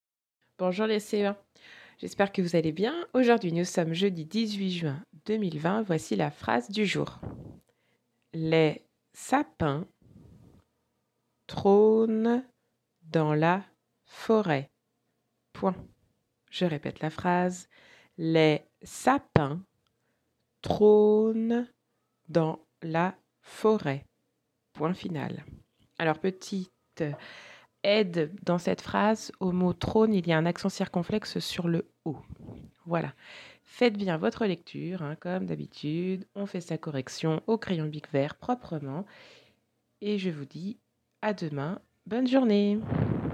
CE1-dictée-18-juin.mp3